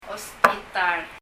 « post office 郵便局 paper 紙 » hospital 病院 osbitar [ɔsp(b)ita:l] 英） hospital 日） 病院 Leave a Reply 返信をキャンセルする。